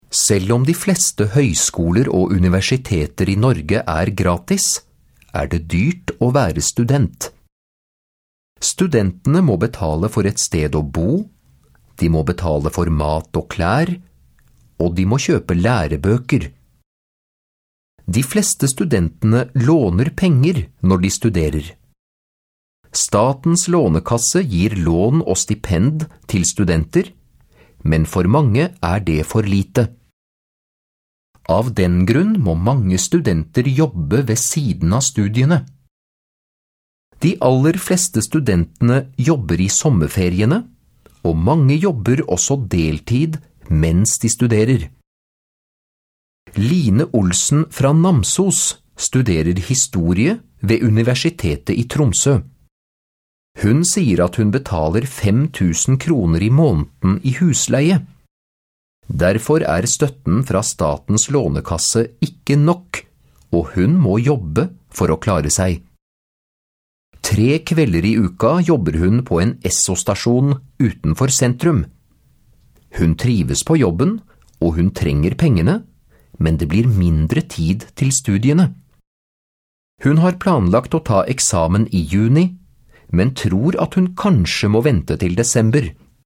Sara hører på et radioprogram. Programmet handler om studenter som har dårlig økonomi.